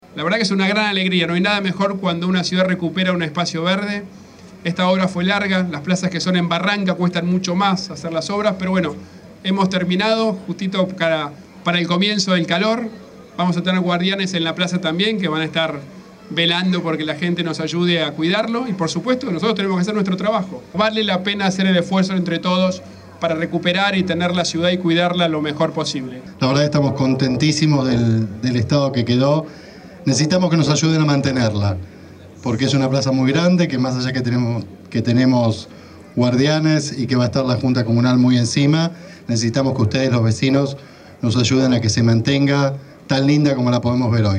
El jefe de Gobierno de la Ciudad Horacio Rodríguez Larreta, encabezó este viernes el acto de reinauguración de las tres plazas de Barrancas de Belgrano, en una puesta en valor del predio que incluyó la reconstrucción de veredas, solados y reparquización, y resaltó la importancia de recuperar los espacios verdes.